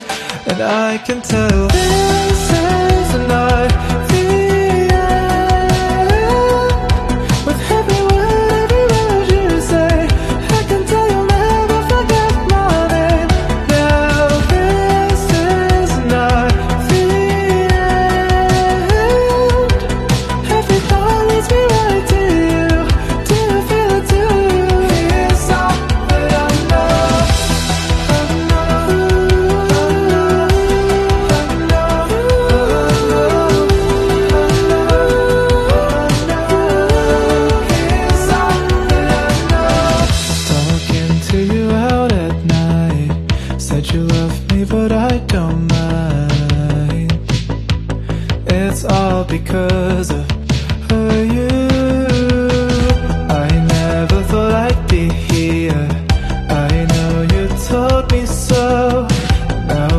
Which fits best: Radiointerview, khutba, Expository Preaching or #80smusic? #80smusic